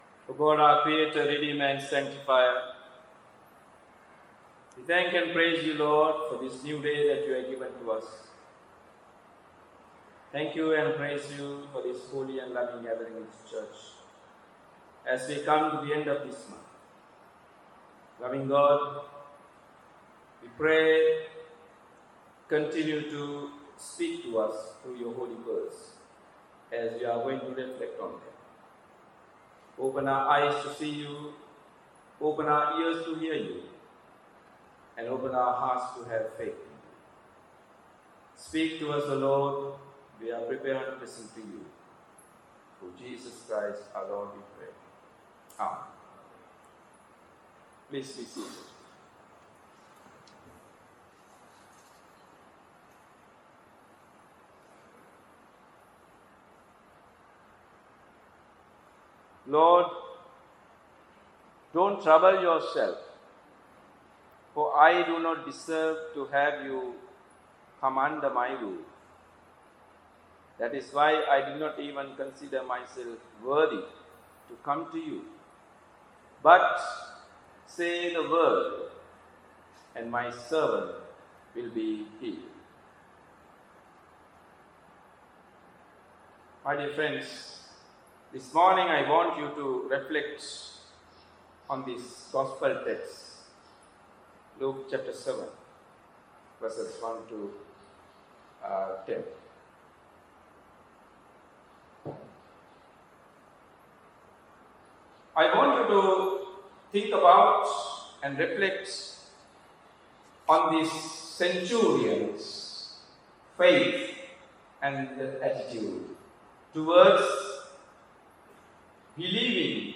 Sermon Description Today’s sermon is from Luke 7.1-10 The healing of the Centurion’s servant teaches us that believing in the WORD of GOD is the basis for the healing of wounds and reconciliation among different communities. God’s Word crosses over all barriers, boundaries, and limitations to do what God has intended.